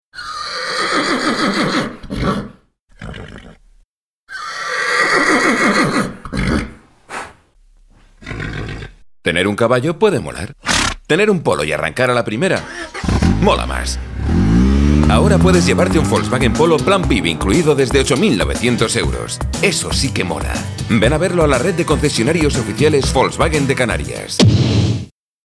VOZ GRAVE, BAJO.
kastilisch
Sprechprobe: eLearning (Muttersprache):